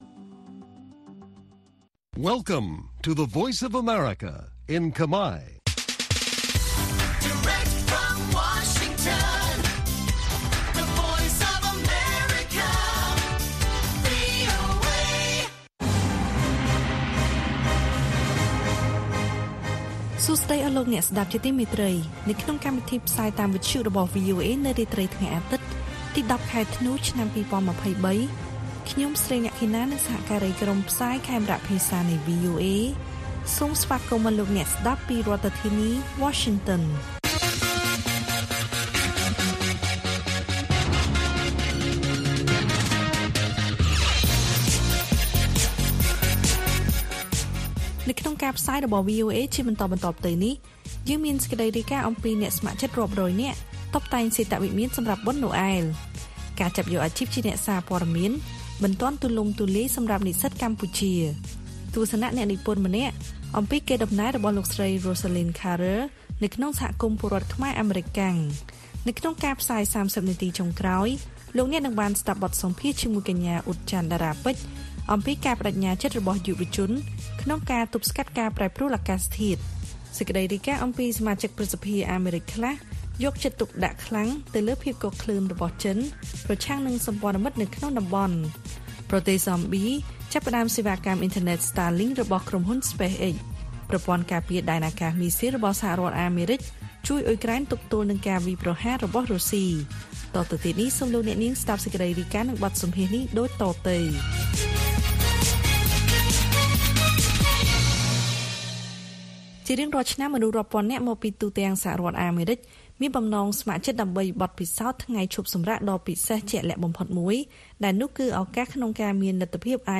ព័ត៌មានពេលរាត្រី ១០ ធ្នូ៖ អ្នកស្ម័គ្រចិត្តរាប់រយនាក់តុបតែងសេតវិមានសម្រាប់បុណ្យណូអែល